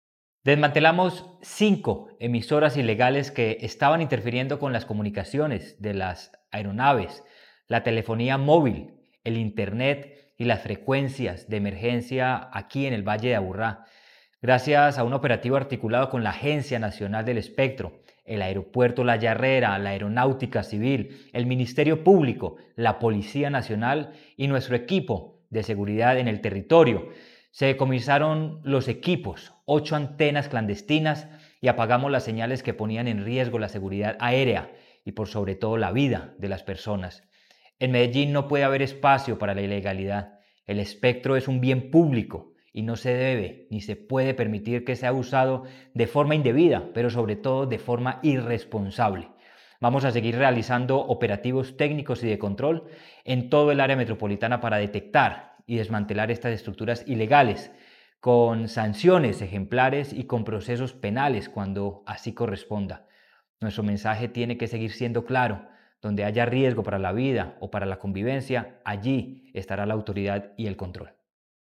Declaraciones secretario de Seguridad y Convivencia, Manuel Villa Mejía
Declaraciones-secretario-de-Seguridad-y-Convivencia-Manuel-Villa-Mejia-2.mp3